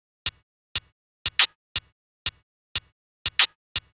Session 08 - Hi-Hat 02.wav